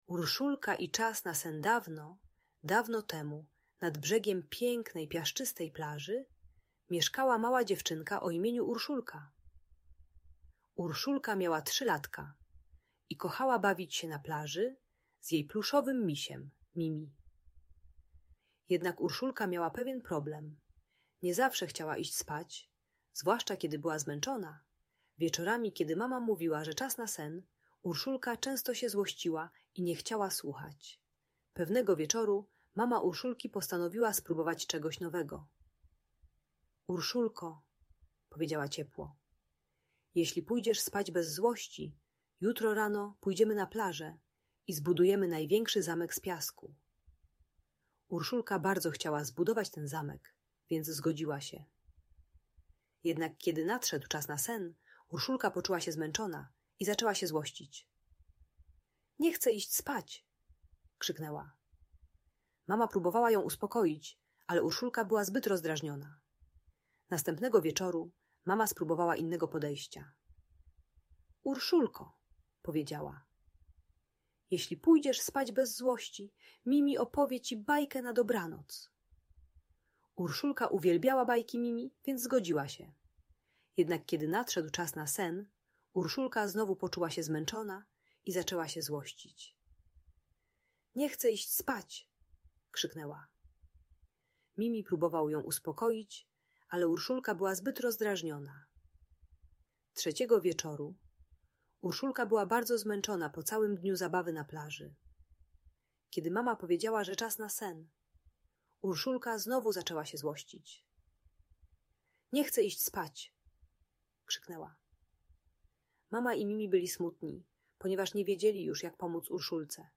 Historia Urszulki i Magicznej Muszelki - Bunt i wybuchy złości | Audiobajka